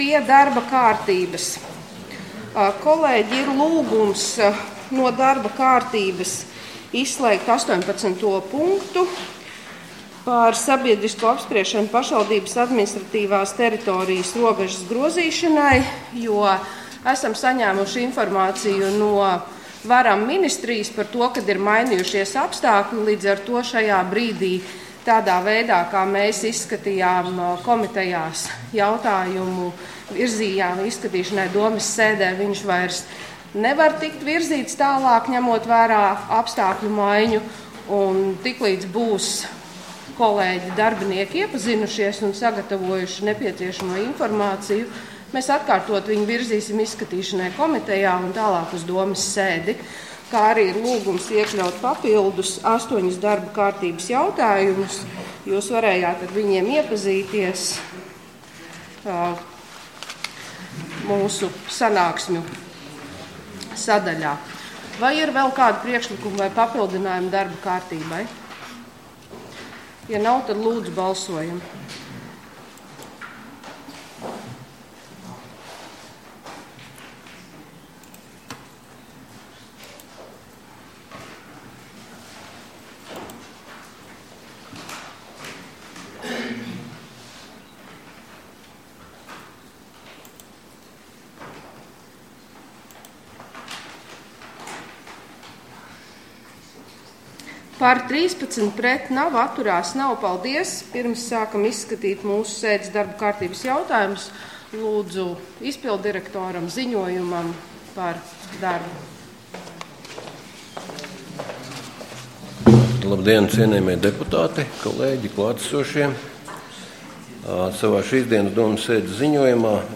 Domes sēde Nr. 15